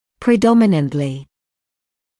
[prɪ’dɔmɪnəntlɪ][при’доминэнтли]преимущественно, особенно